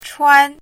汉字“氚”的拼音是：chuān。
氚的拼音与读音
chuān.mp3